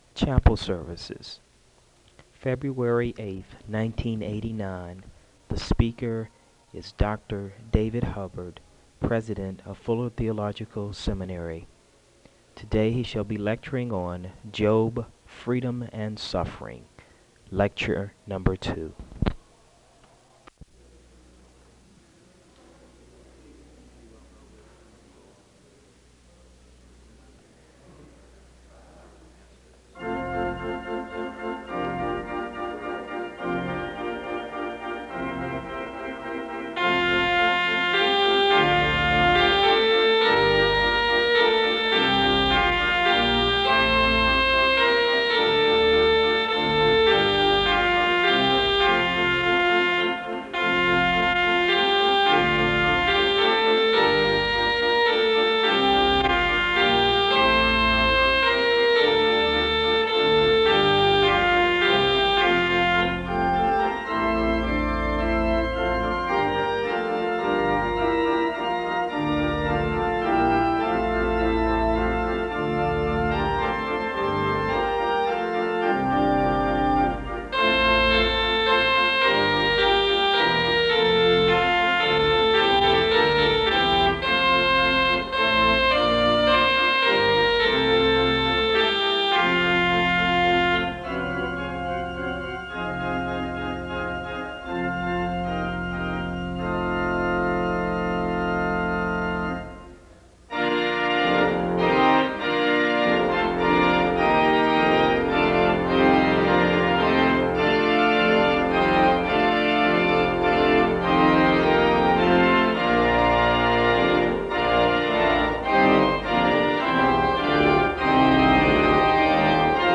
Home SEBTS Adams Lecture